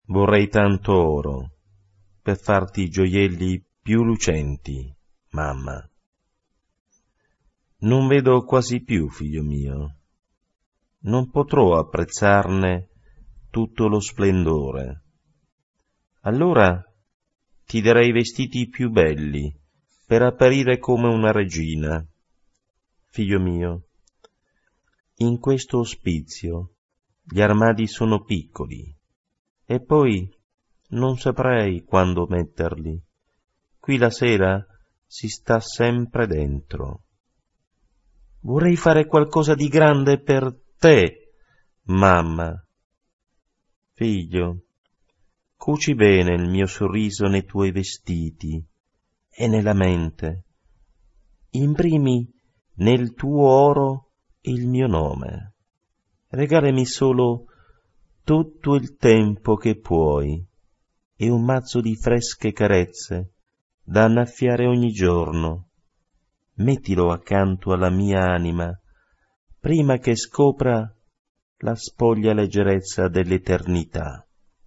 Poesia Sonora in MP3 e amore familiare.